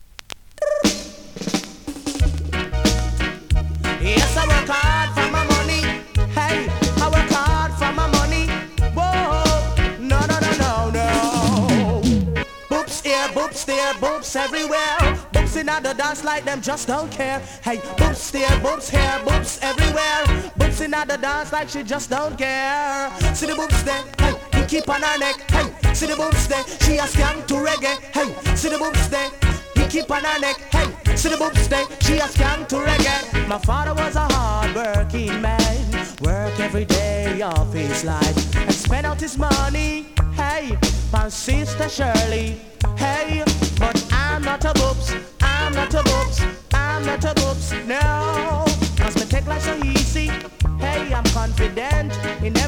2026 NEW IN!! DANCEHALL!!
スリキズ、ノイズ比較的少なめで